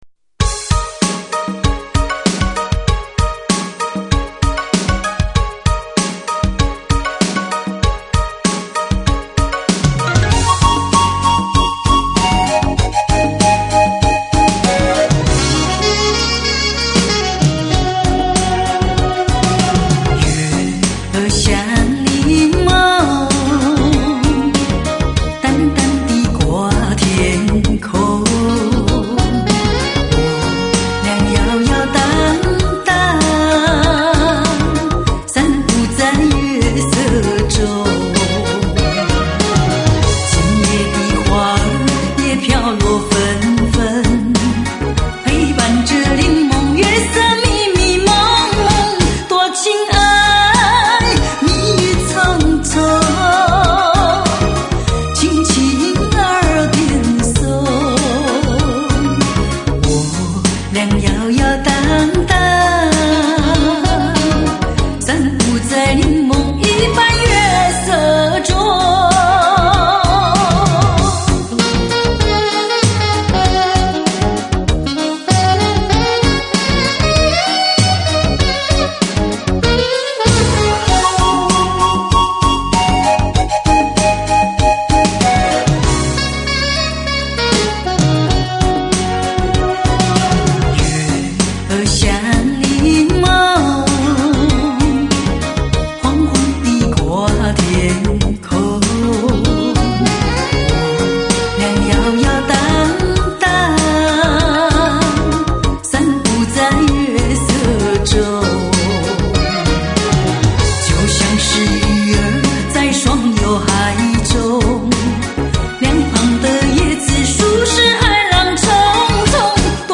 高音质  360网盘  ★ ★ ★ ★ ★下载地址